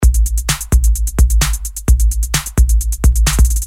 Electro rythm - 130bpm 30